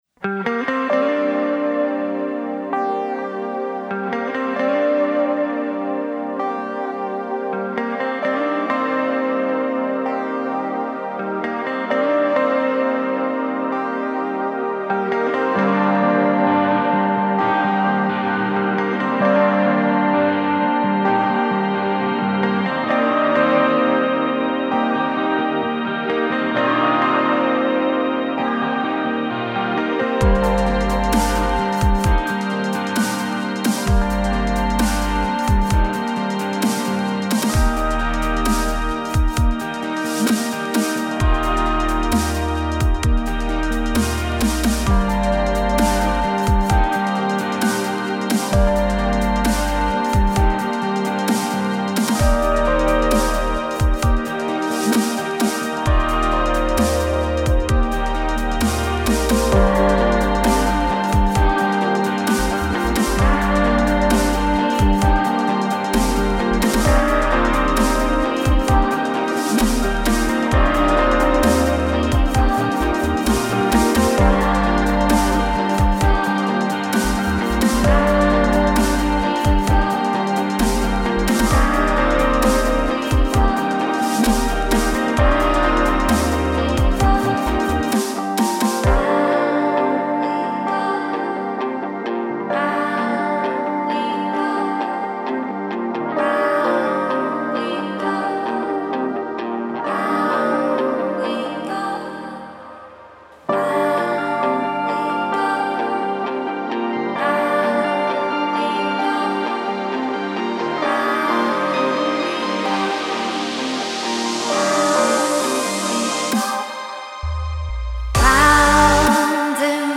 Indie Electro Rock